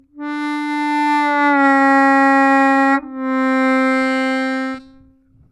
-1 -1’+1 плавно